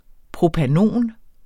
Udtale [ pʁopaˈnoˀn ]